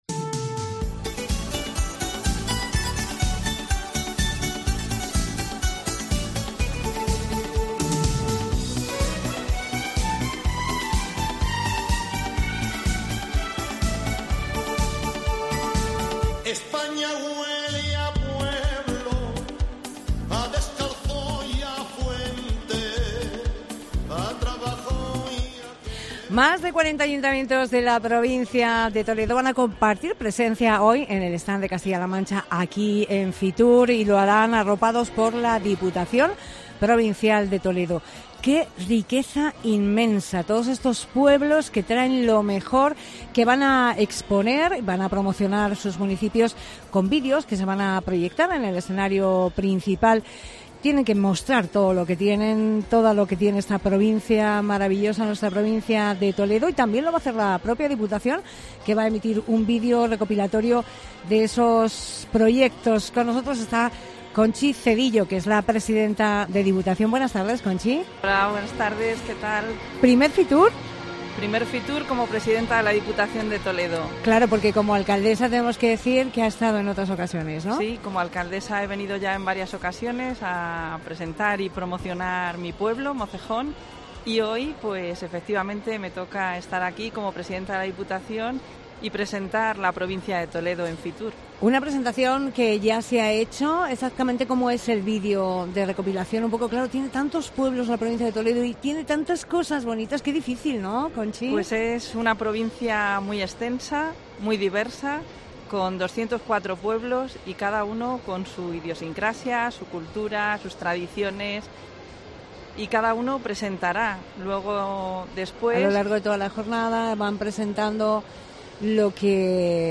FITUR | Entrevista a Conchi Cedillo, presidenta de la Diputación de Toledo